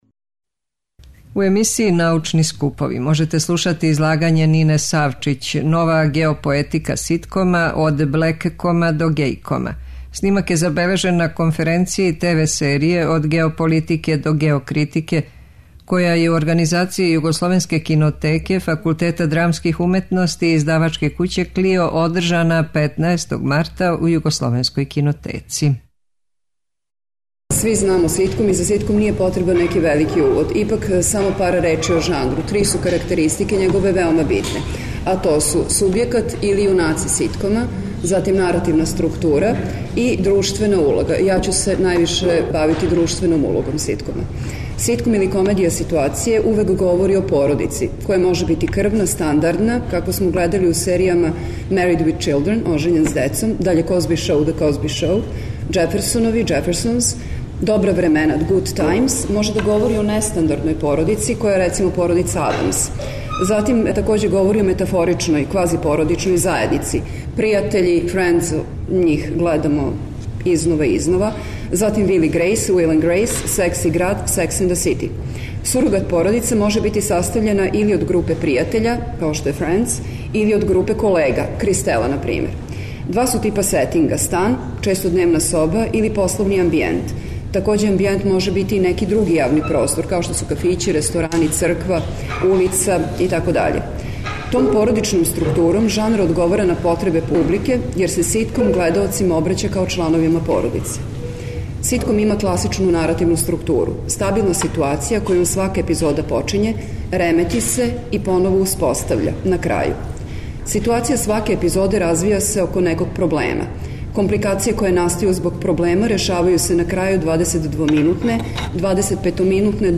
преузми : 7.10 MB Трибине и Научни скупови Autor: Редакција Преносимо излагања са научних конференција и трибина.